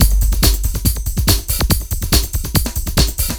___BIG BEAT 2.wav